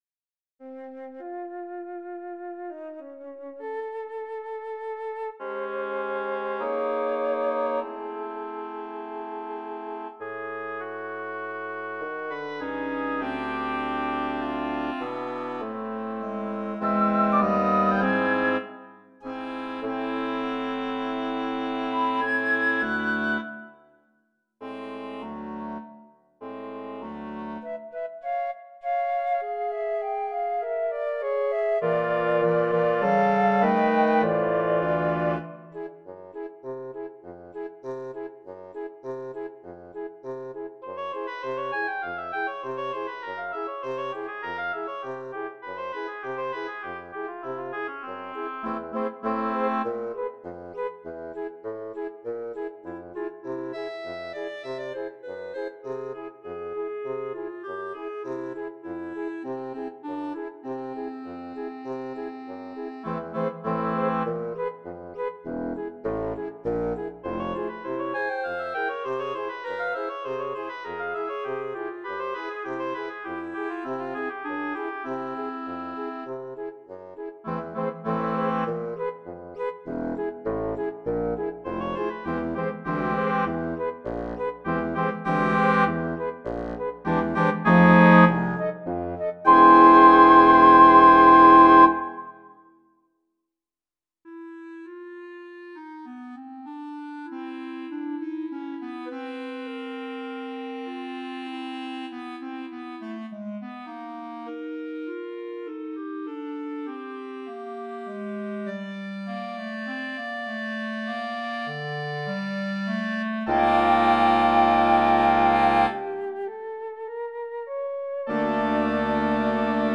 Wind Octet